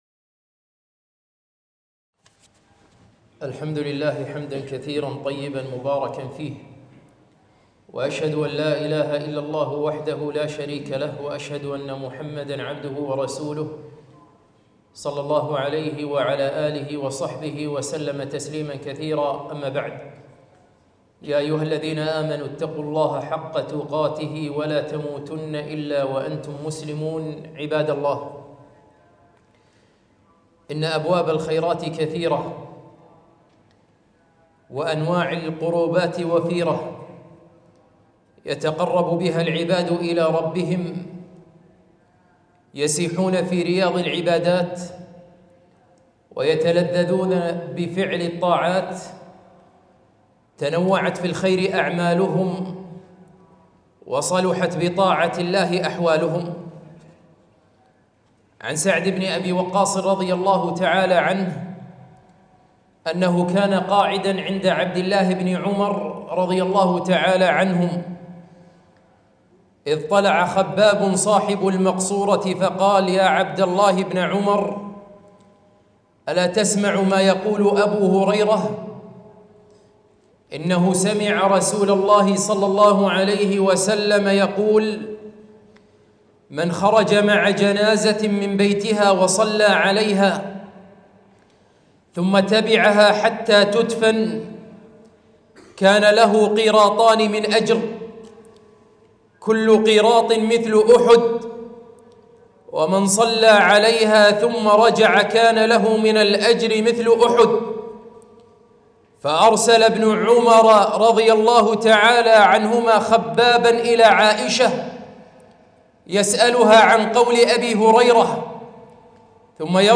خطبة - أبواب الخير كثيرة